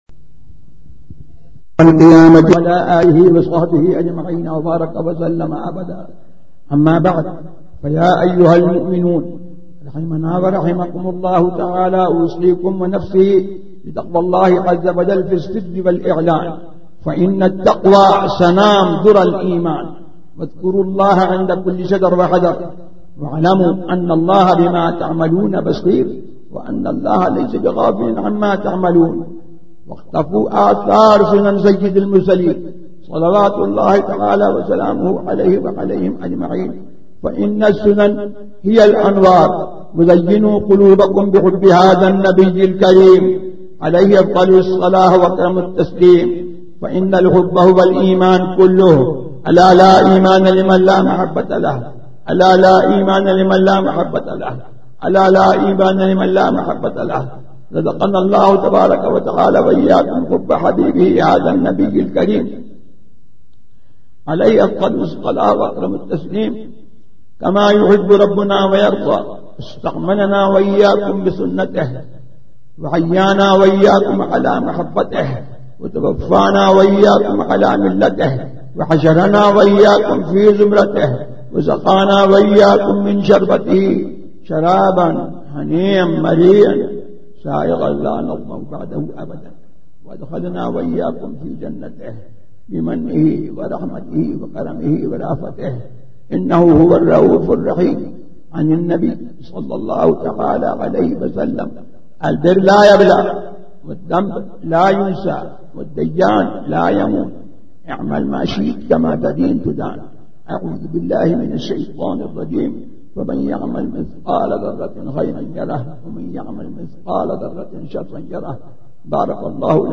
Khutba e Juma – Ittihad e Ahlesunnat